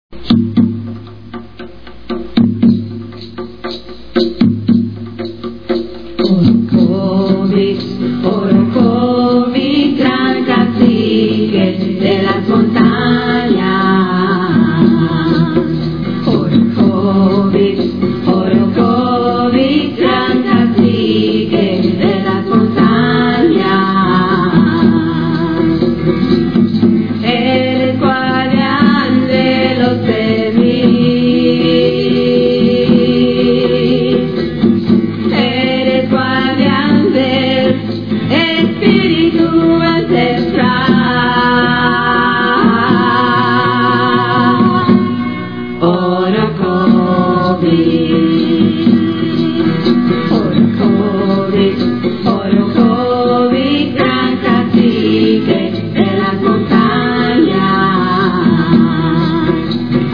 Música Taína (los indígenes)